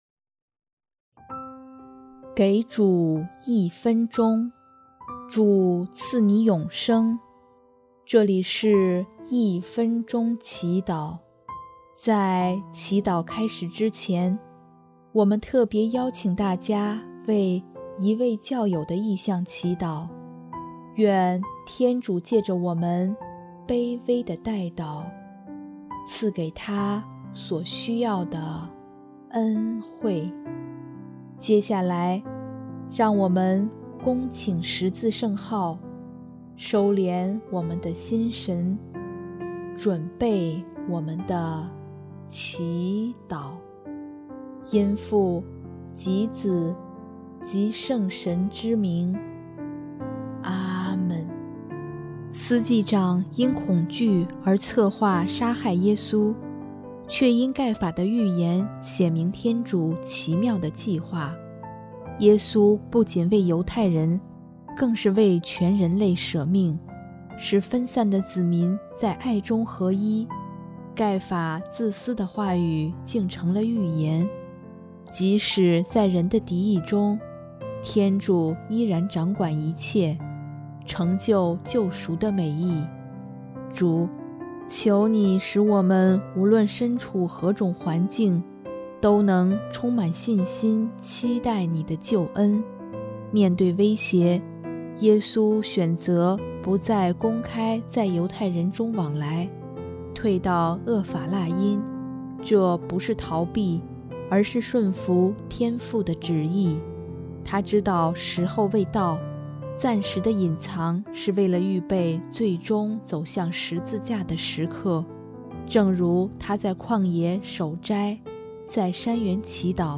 【一分钟祈祷】|4月12日 政治算计与神圣预言
音乐：第四届华语圣歌大赛参赛歌曲《受难记后续》（一位教友：求主带领正在参加这五天避静的弟兄姐妹们祈祷）